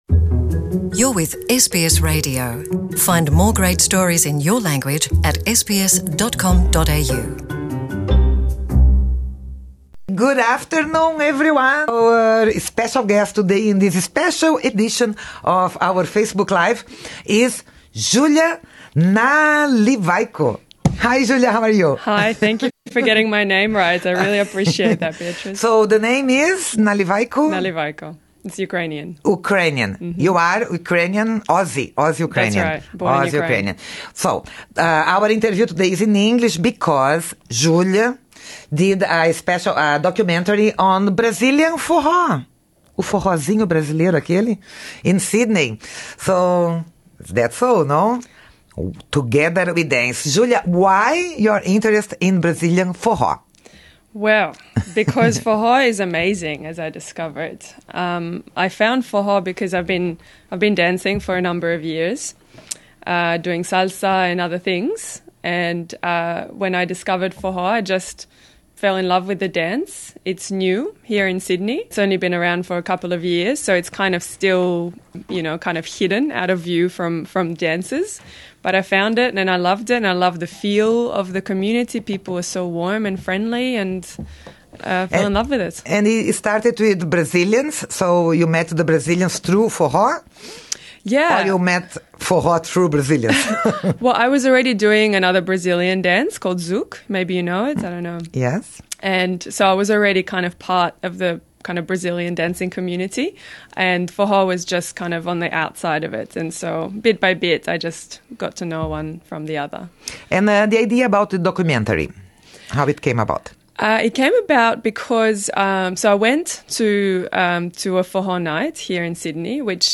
A entrevista foi feita em inglês.